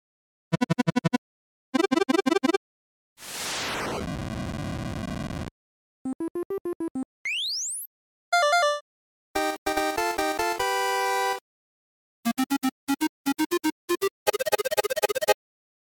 Восьмибитные звуки из ретро видеоигр для монтажа видео и просто вспомнить детство.